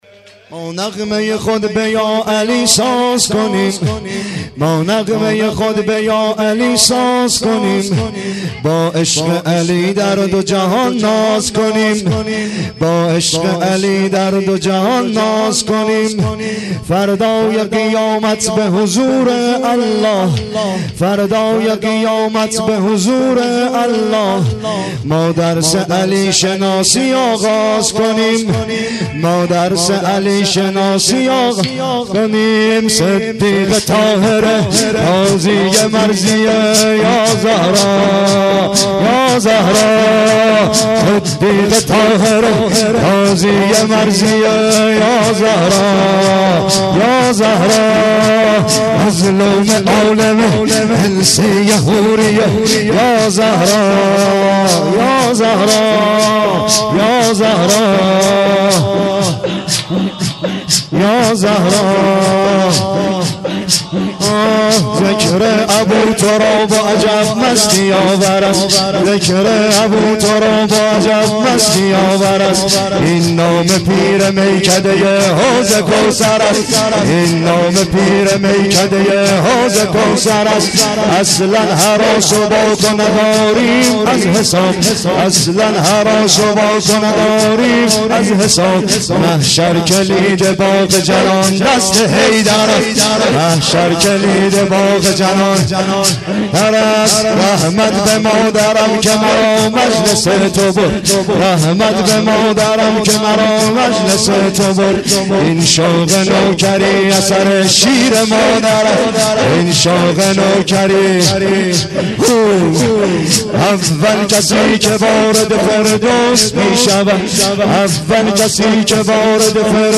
ما نغمه ی خود به یا علی ساز کنیم(سینه زنی/ تک